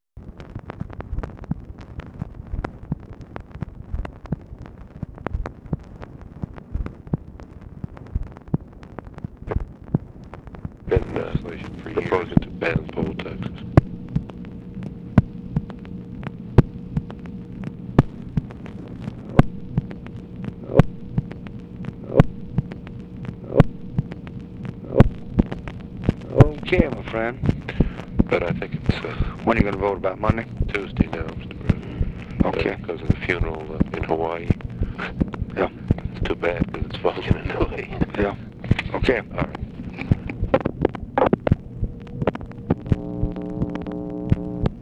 Conversation with NICHOLAS KATZENBACH, May 6, 1965
Secret White House Tapes